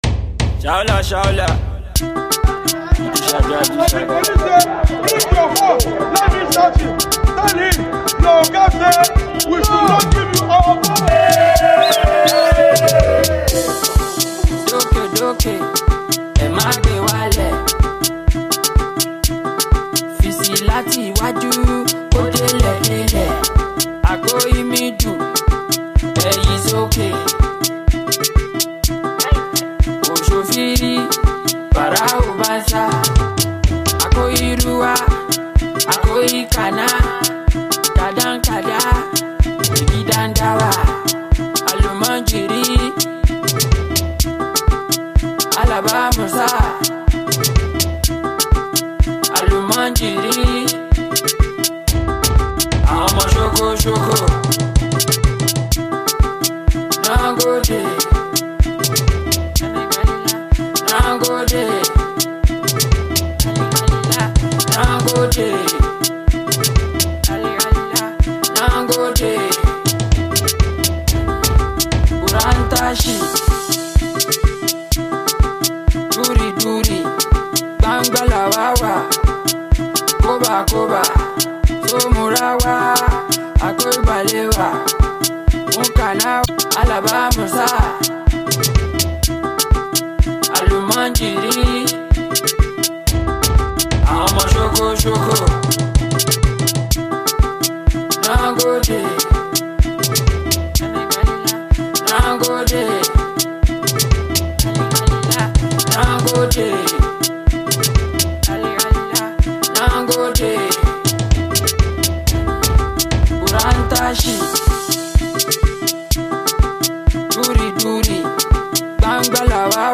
Street-hop
a track that blends gratitude with upbeat energy.